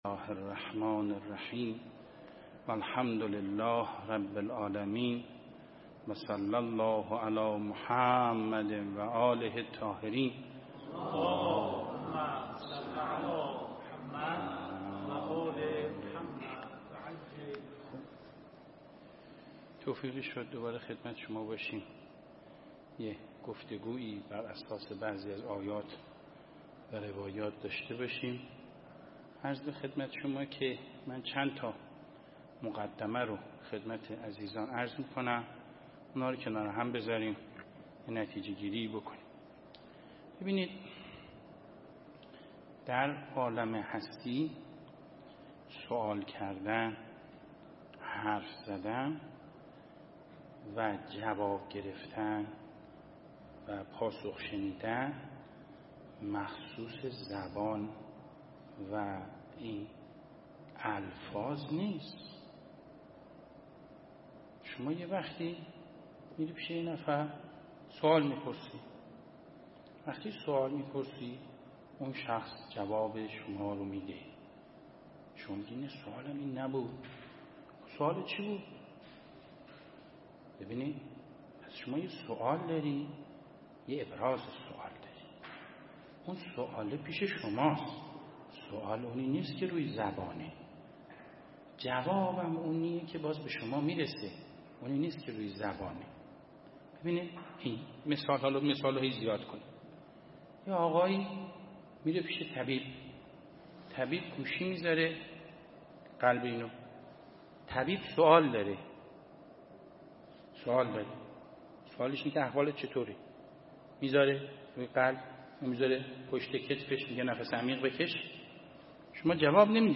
درس الاخلاق
🔰 درس اخلاق هفتگی